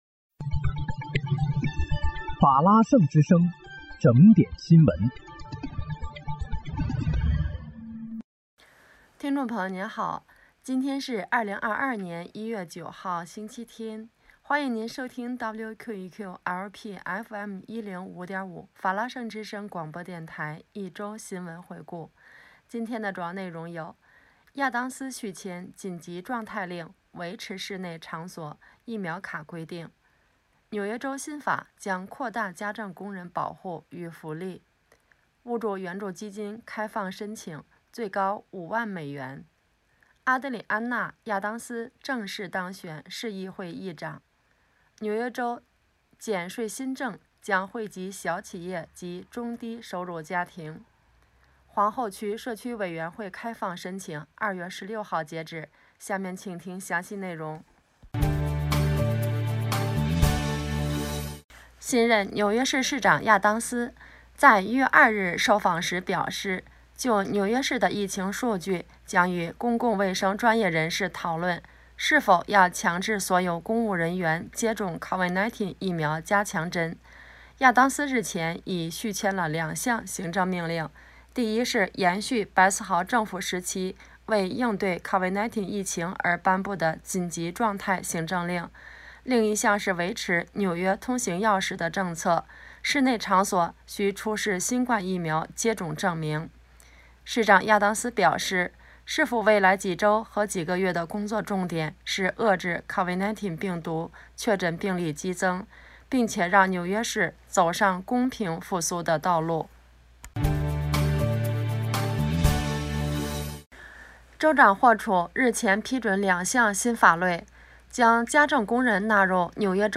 1月9日(星期日）一周新闻回顾